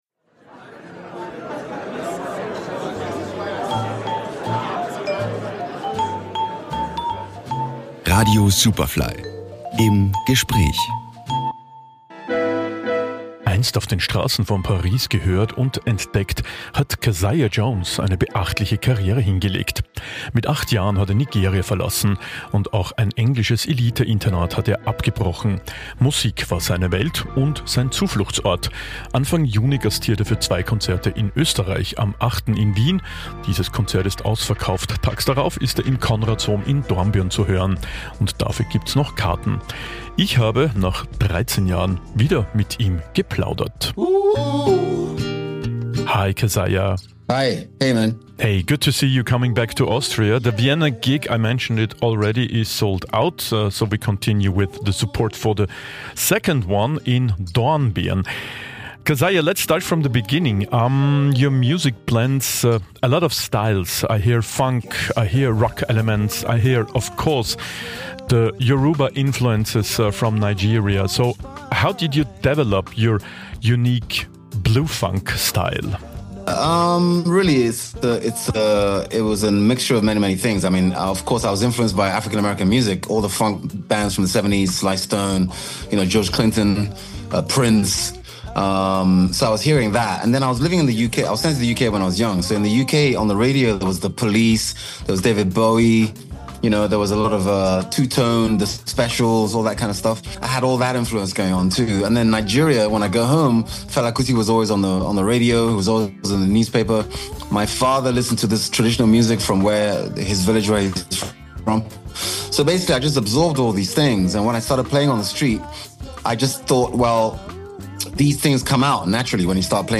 Superfly Interviews | Keziah Jones